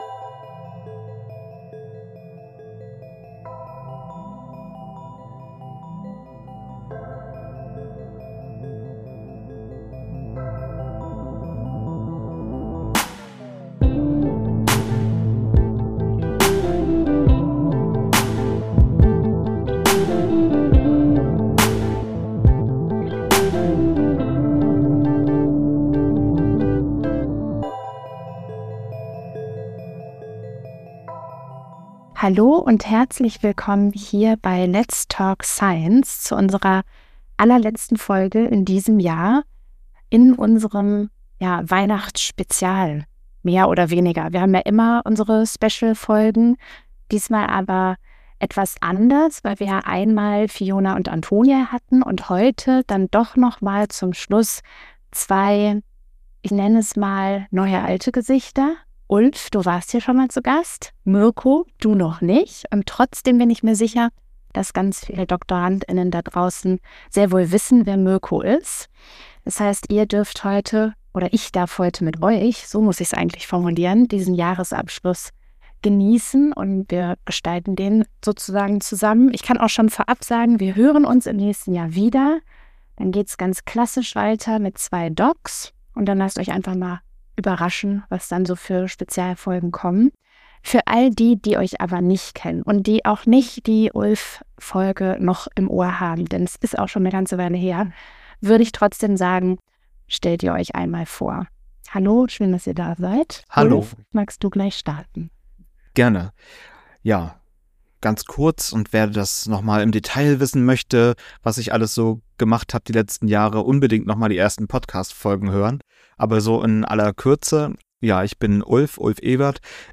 Eine ruhige, nachdenkliche Jahresabschlussfolge über Austausch, Haltung und den Wert von Wissenschaftskommunikation – gerade in Zeiten des Wandels.